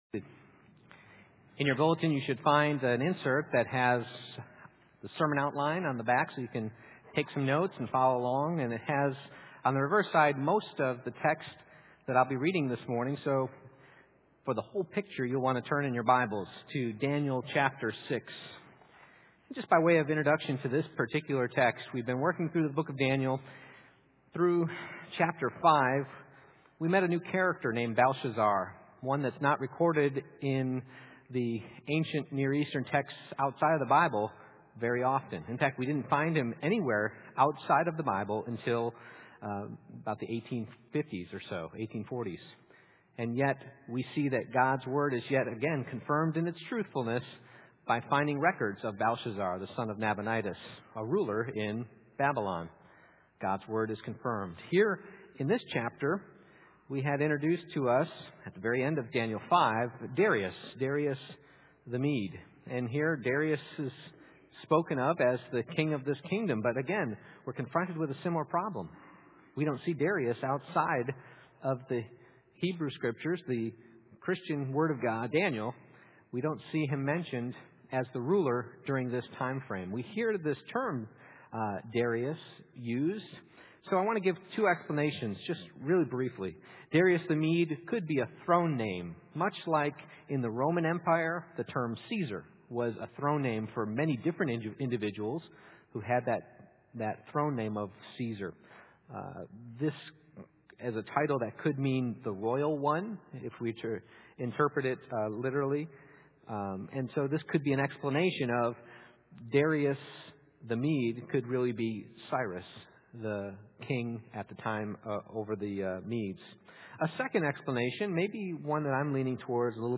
Exposition of Daniel Passage: Daniel 6:1-28 Service Type: Morning Worship « Can you read the Writing on the Wall?